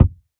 Minecraft Version Minecraft Version 1.21.5 Latest Release | Latest Snapshot 1.21.5 / assets / minecraft / sounds / block / packed_mud / step4.ogg Compare With Compare With Latest Release | Latest Snapshot